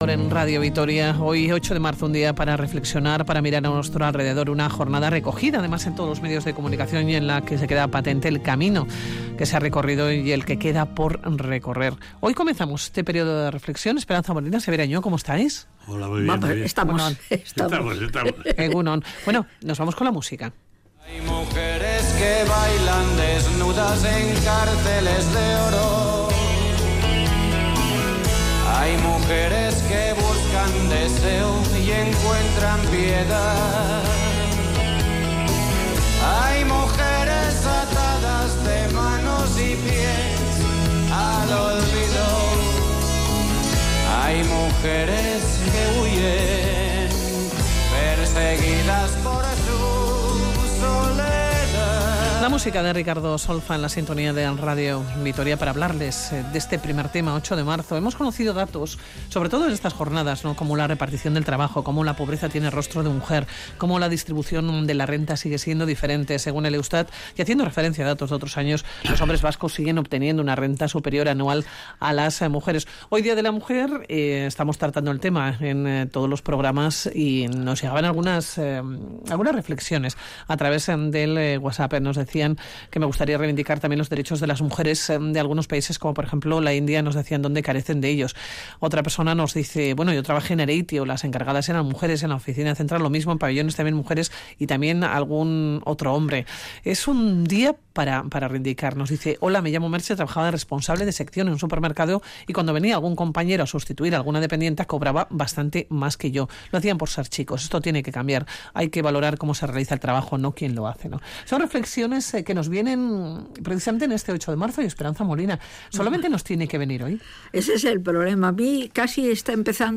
Audio: Tertulia de sabios que hoy viene marcada por el Día Internacional de la Mujer.